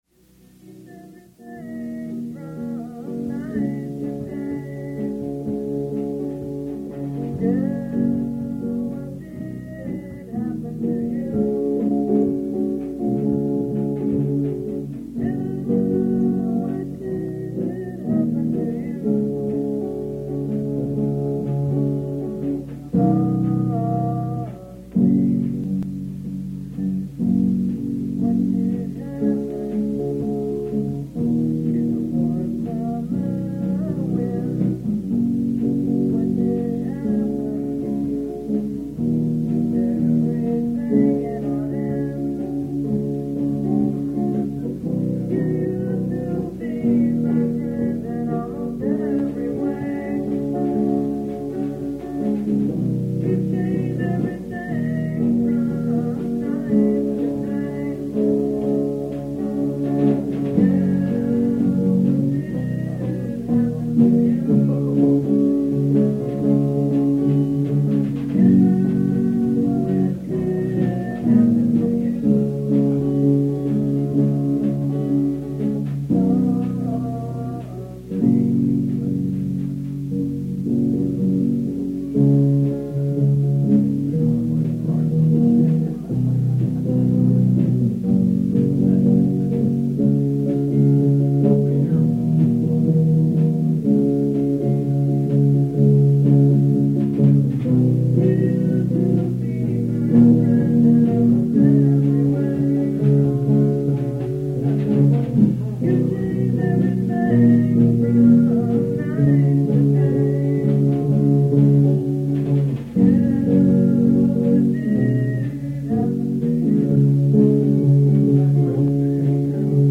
Vocal, Guitar
Drums
Bass
(way, way) Back-Vocal, Guitar